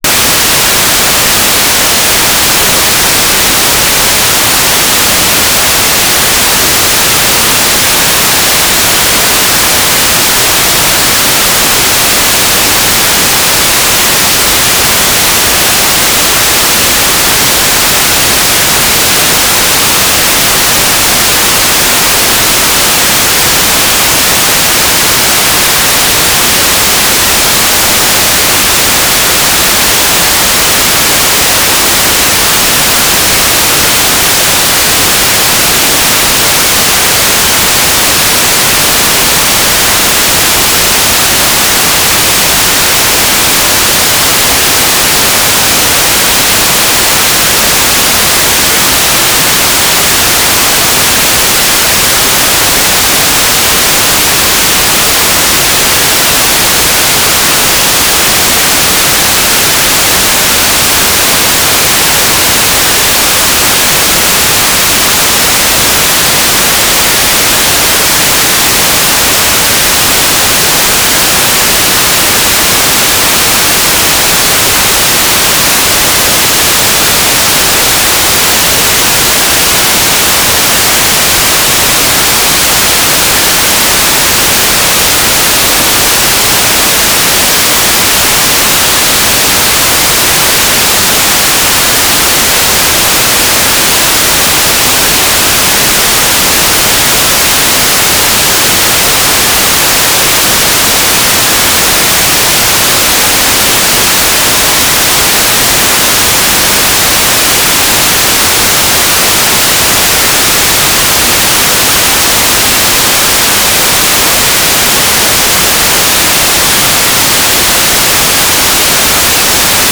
"transmitter_description": "Mode 1200bps FFSK",
"transmitter_mode": "FFSK",